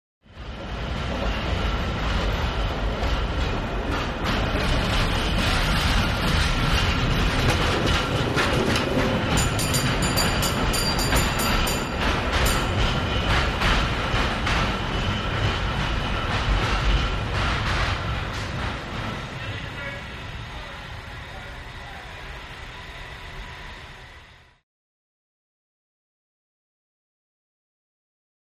Cable Car By Close With Bell Ring, Loud Metal Rail Thumps.